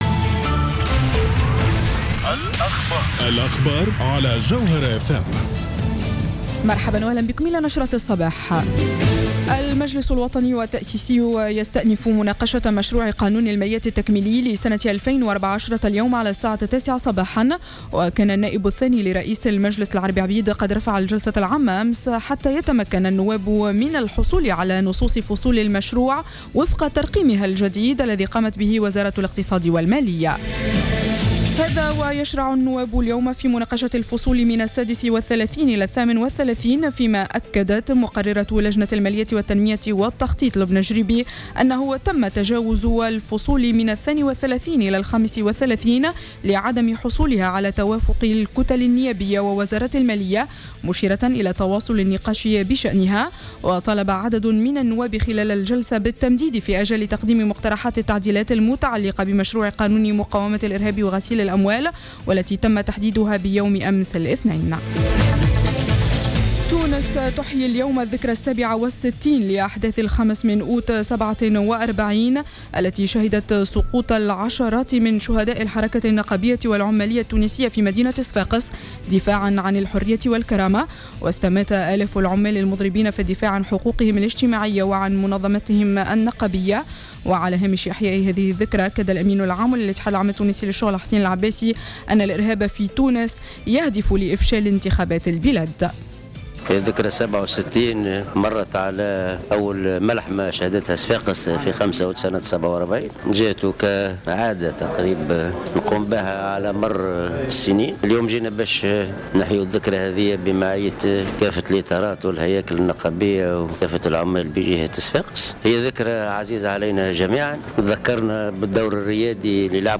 نشرة أخبار السابعة صباحا ليوم الثلاثاء 05-08-14